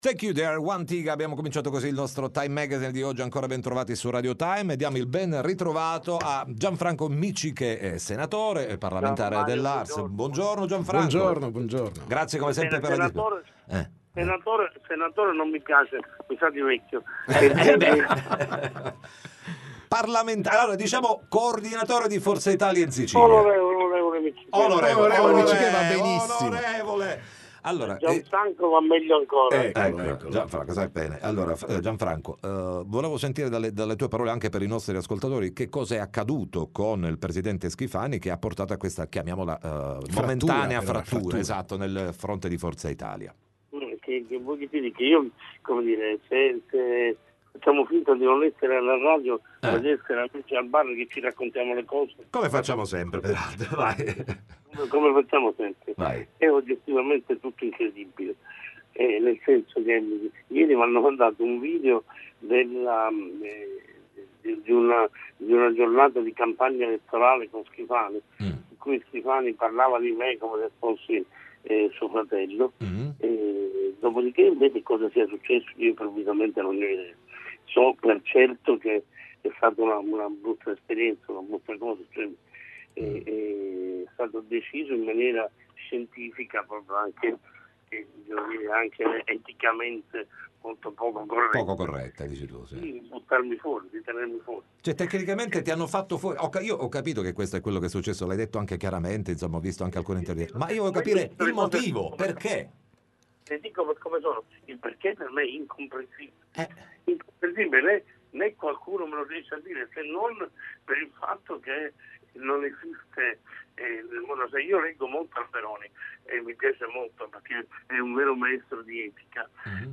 TM Intervista Gianfranco Miccichè